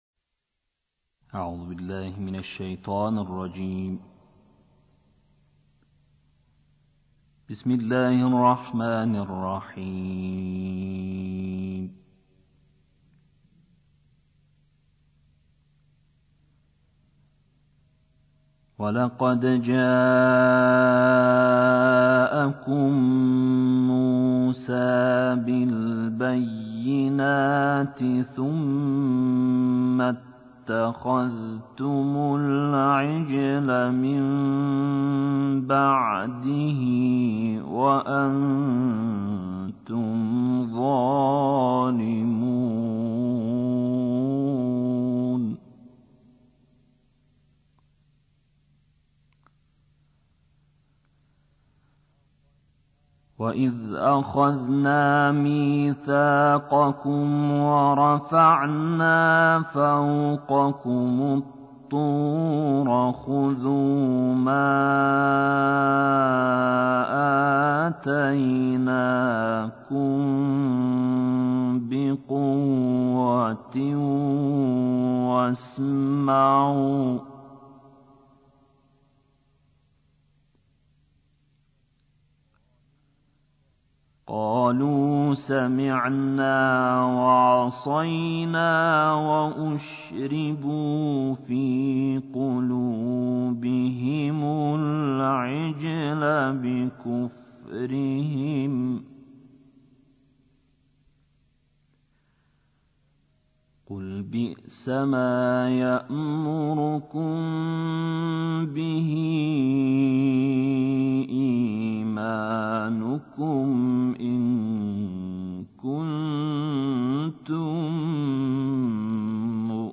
دانلود قرائت سوره بقره آیات 92 تا 103